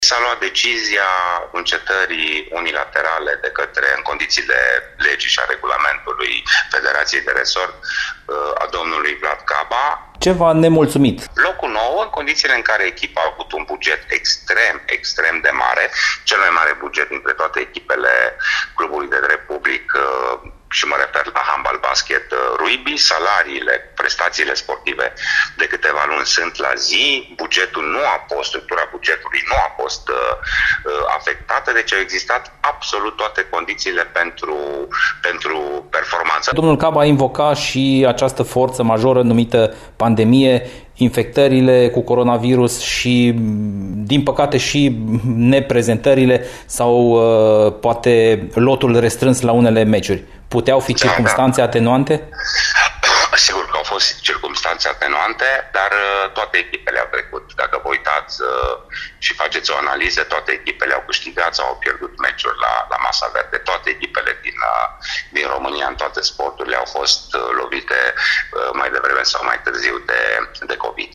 Replica SCM Timișoara a venit din partea consilierului local Radu Țoancă, membru în Consiliul de Administrație al clubului de drept public. Țoancă a invocat strict locul al 9-lea ocupat în final de campionat, considerat mult inferior cu bugetul atribuit echipei de handbal în acest an: